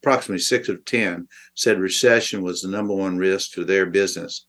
during a video briefing on Friday